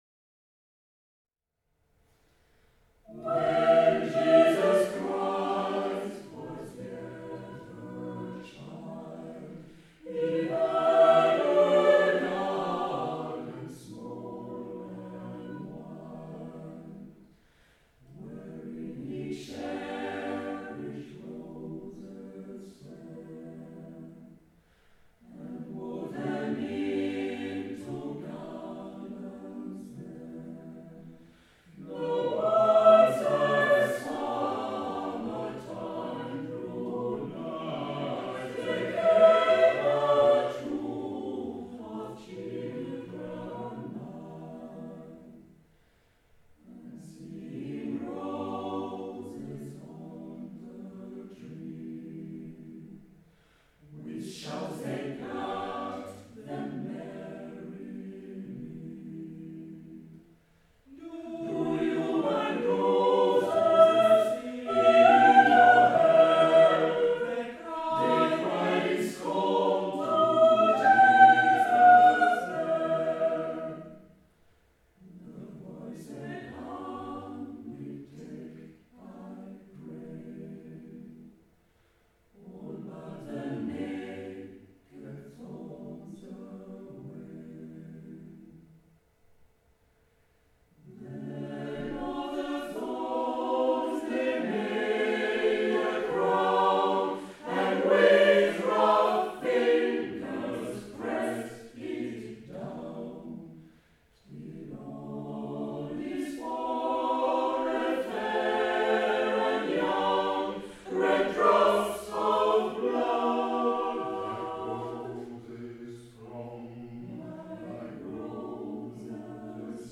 Un petit extrait romantique,